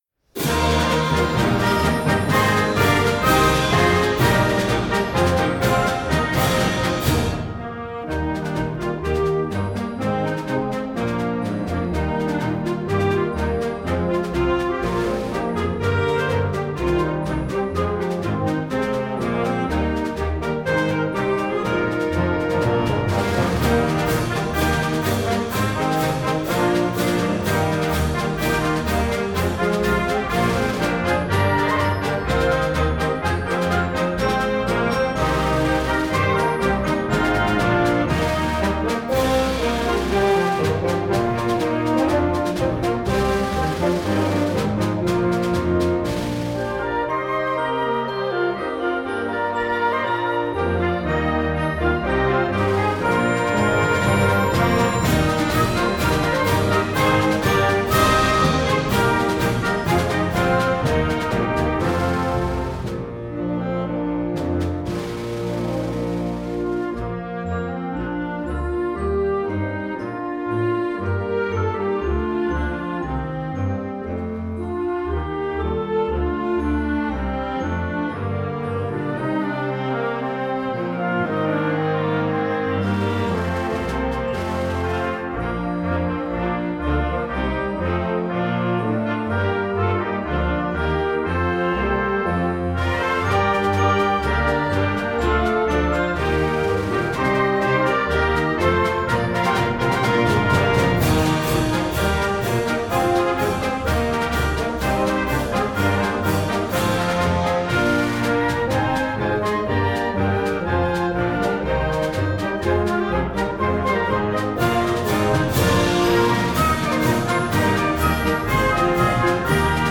Gattung: Konzertmarsch für Jugendblasorchester
Besetzung: Blasorchester
frische, moderne Konzertmarsch